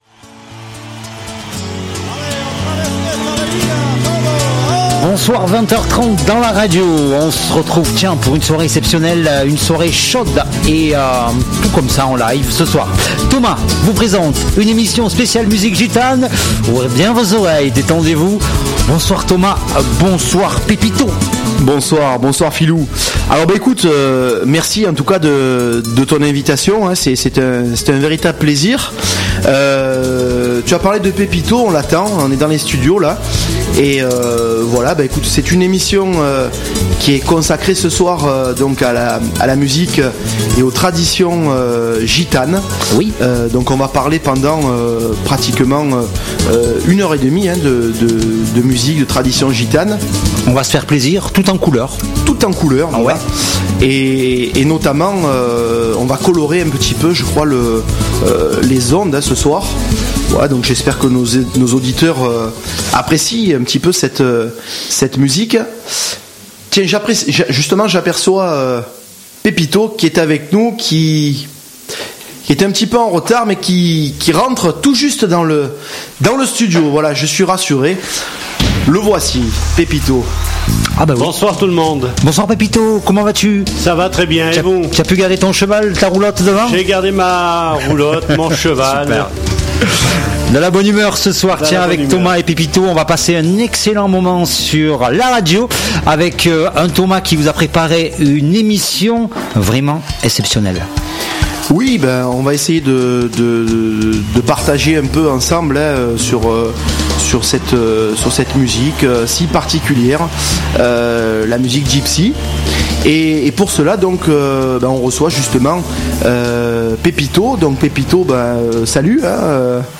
Olé, spécial musique gitane